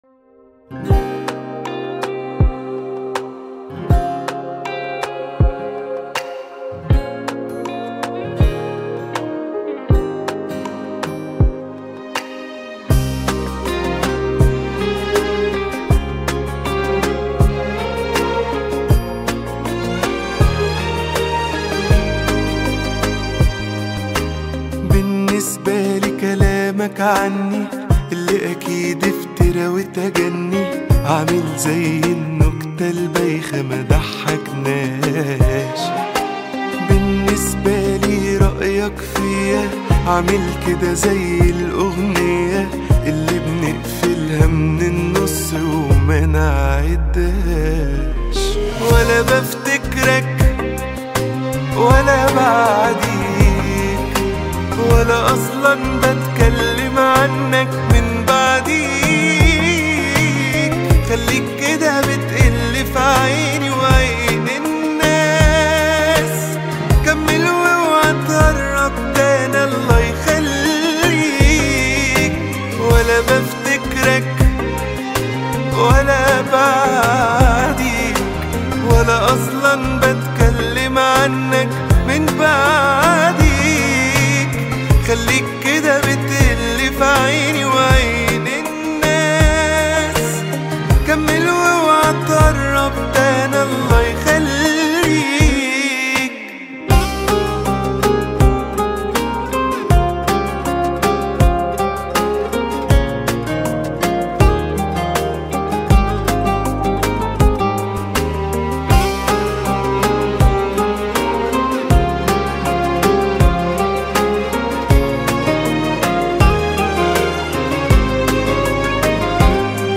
وتعكس لحنًا يوازن بين الشجن والقوة.